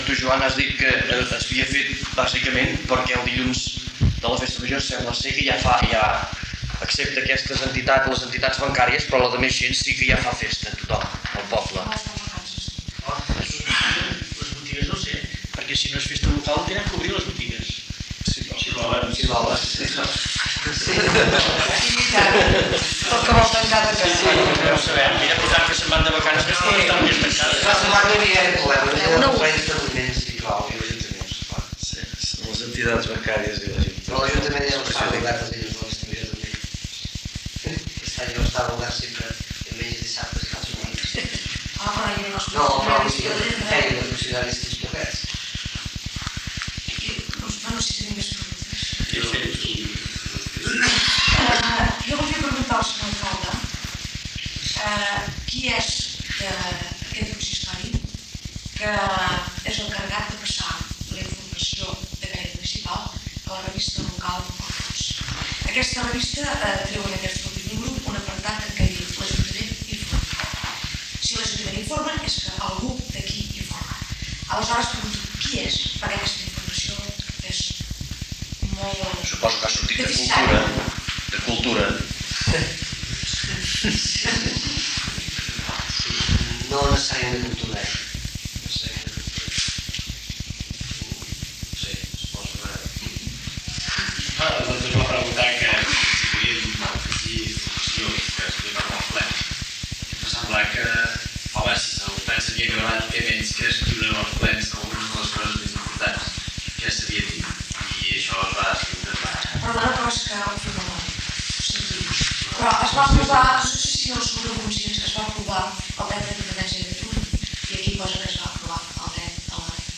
Transmissió del ple municipal de l'Ajuntament de Balenyà